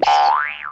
bounce1.wav